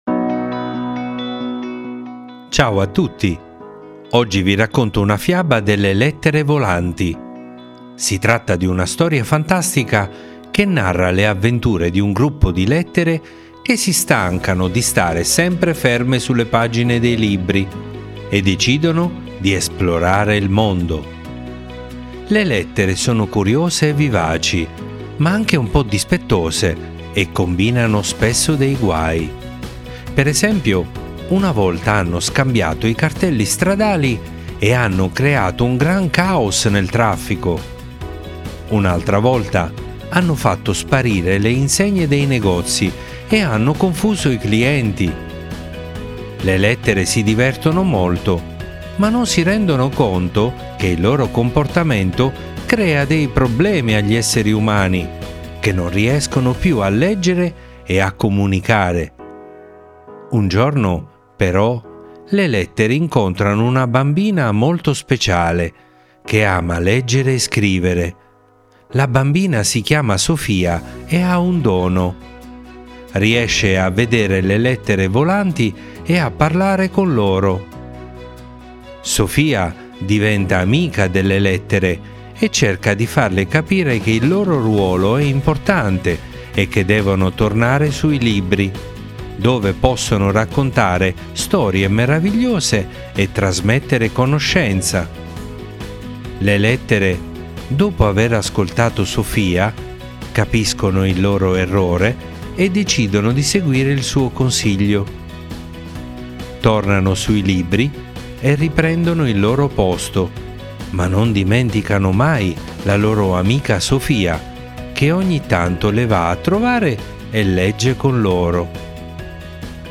mamma legge la fiaba